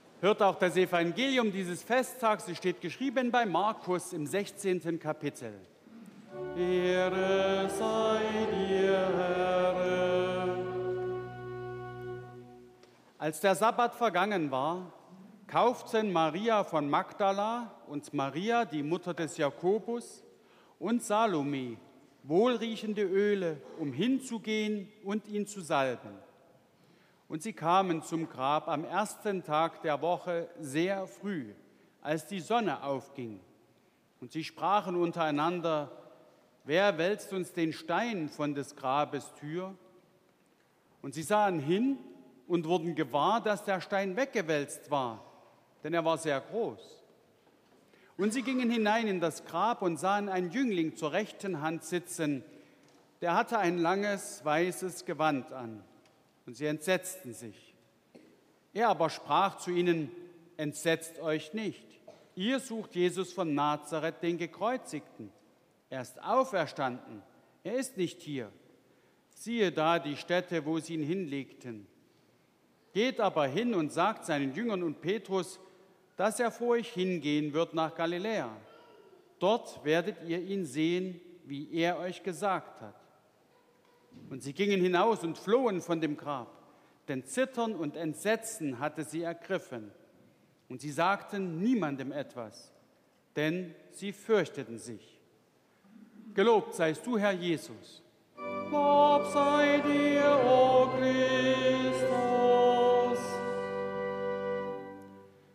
9. Lesung aus Markus 16,1-8 Evangelisch-Lutherische St. Johannesgemeinde Zwickau-Planitz
Audiomitschnitt unseres Gottesdienstes am Ostersonntag 2023.